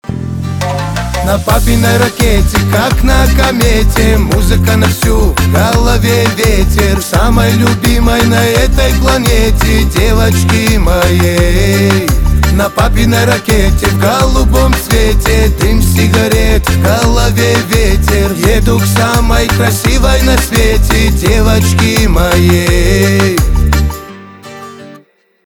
кавказские
битовые , аккордеон